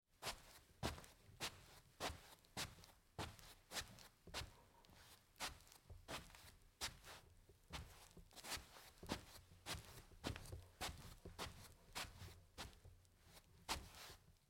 Звуки ковра
Прогулка в тапочках по ковру